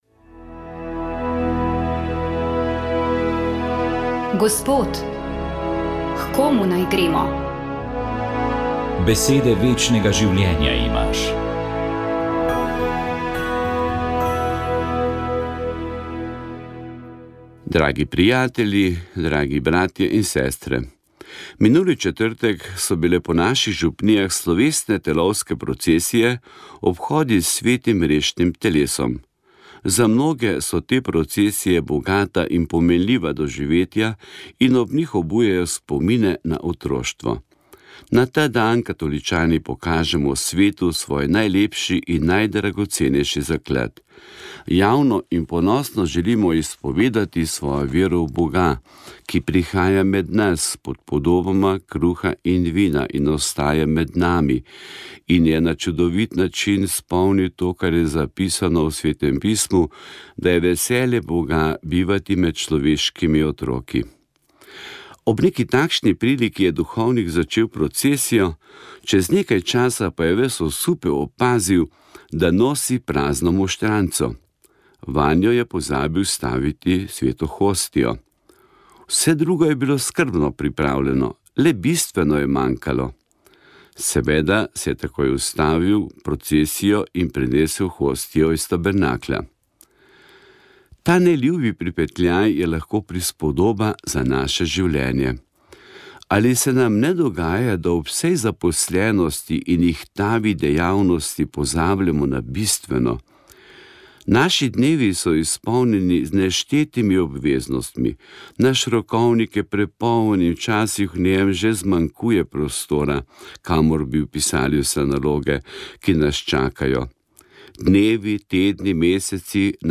Duhovni nagovor
Duhovni nagovor je pripravil ljubljanski pomožni škof msgr. dr. Anton Jamnik.